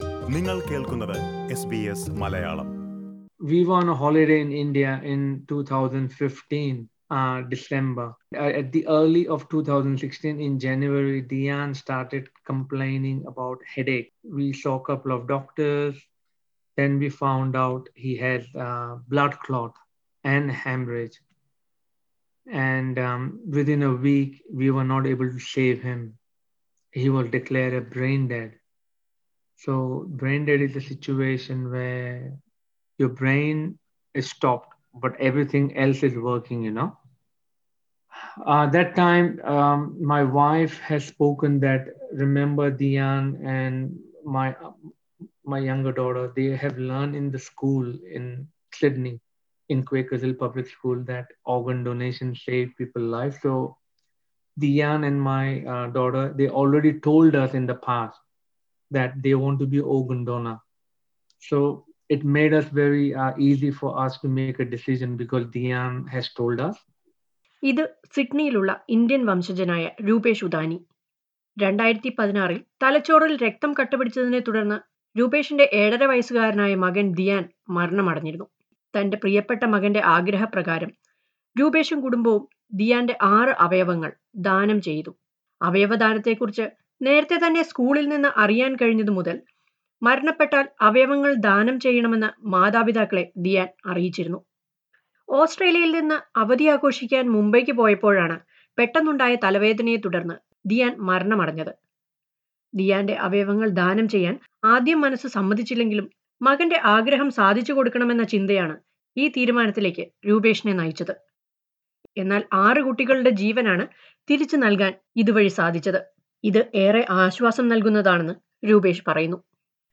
As organ donation is more among migrant communities since 5-10 years, more migrants are being called to register for organ donation that can save many lives battling for life. Listen to a report on this.